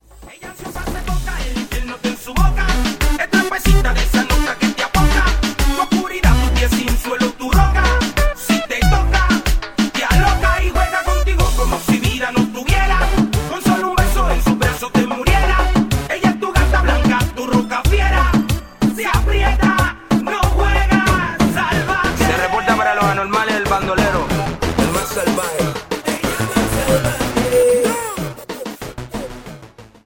Reggaeton Charts - November 2008